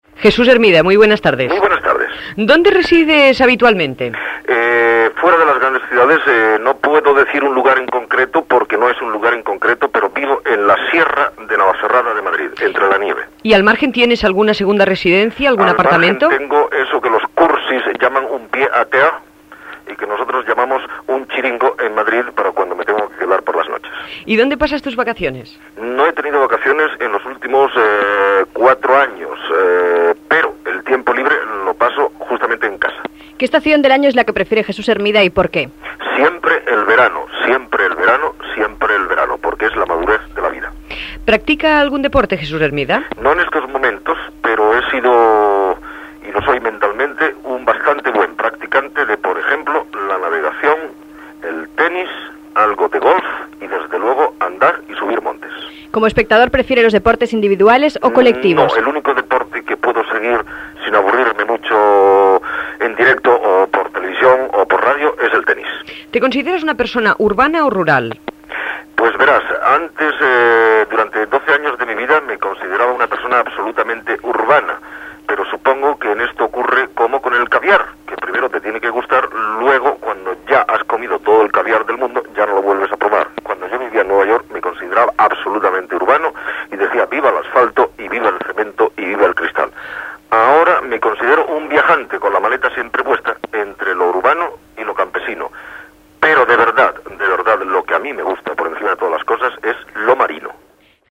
Secció "Sabahombres" Entrevista al presentador Jesús Hermida
Entreteniment